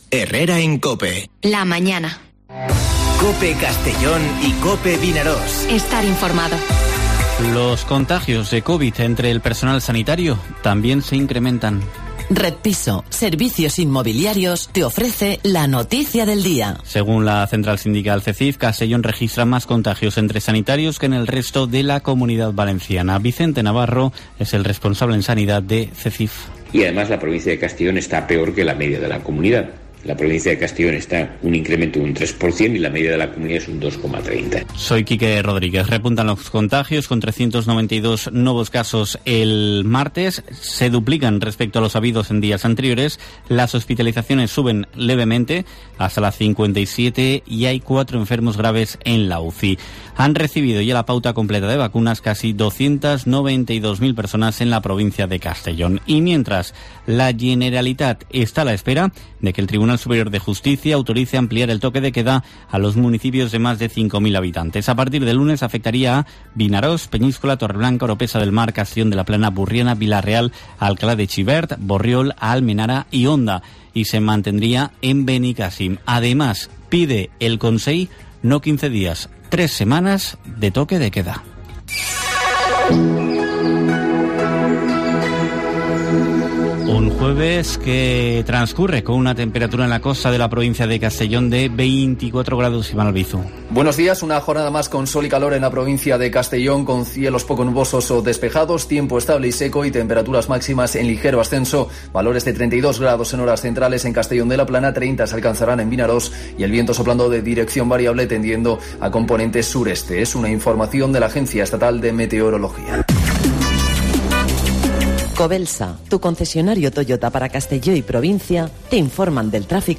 Informativo Herrera en COPE en la provincia de Castellón (21/07/2021)